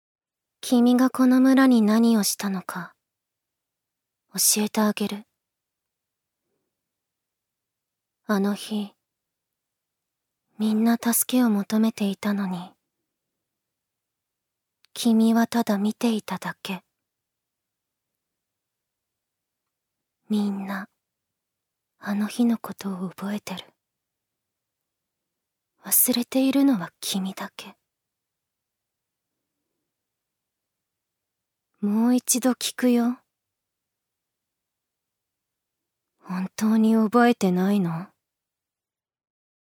女性タレント
セリフ５